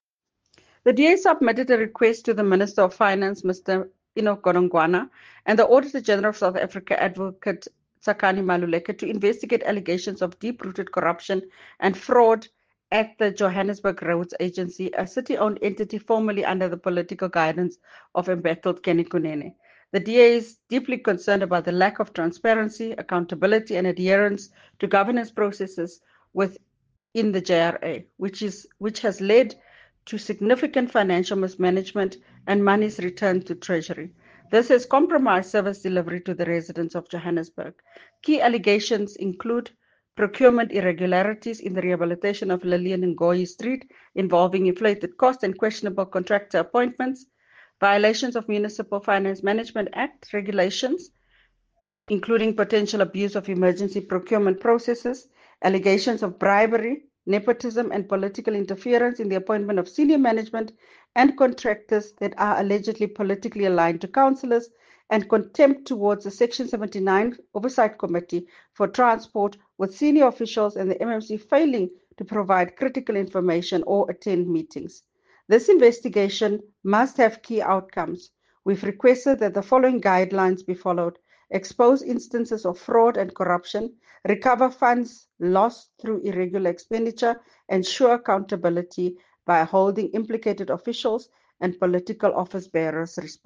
Note to Editors: Please find an English soundbite by Cllr Belinda Kayser-Echeozonjoku
Belinda_ENG_Urgent-investigation-needed-into-alleged-fraud-at-JRA.mp3